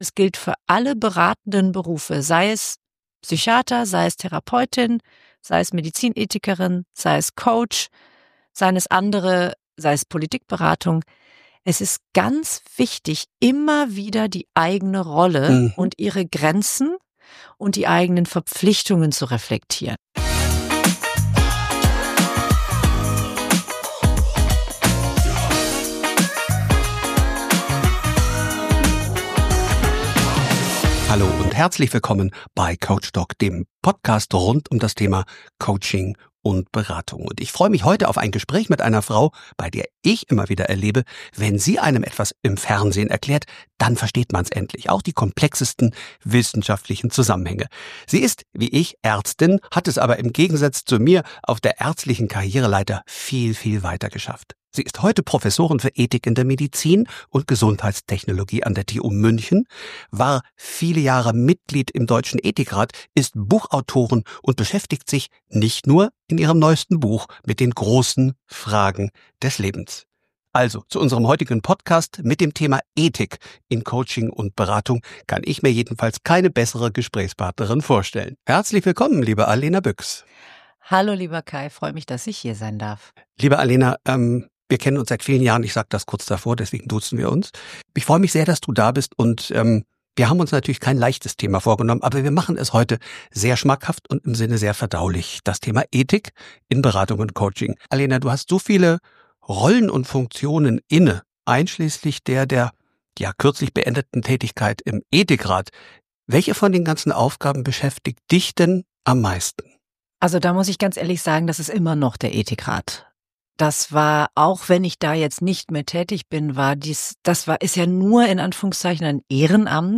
Beschreibung vor 8 Monaten Im Gespräch mit Professor Dr. med. Alena Buyx (Medizinerin, Ärztin, Lehrstuhlinhaberin für Ethik in der Medizin und Medizintechnologie an der TU München und ehemalige Vorsitzende des Deutschen Ethikrats) geht es um die Frage, was Ethik bedeutet und was ethisches Verhalten in Beratungssituationen ausmacht. Wir sprechen über eigene Erfahrungen mit ethischen Dilemmata - sowohl in der Therapie und in Coaching, aber auch in Organisationen.